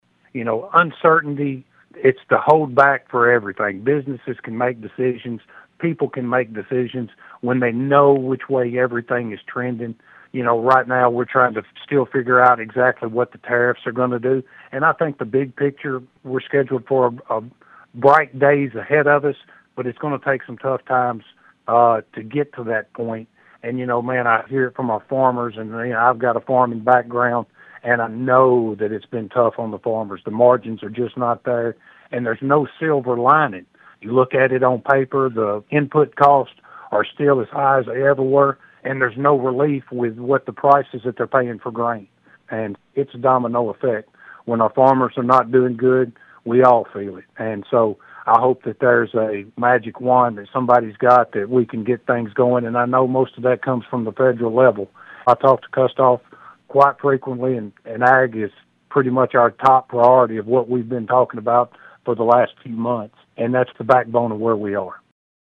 With President Trump beginning his second year in office, Representative Darby was asked about the effects of budget work for lawmakers.(AUDIO)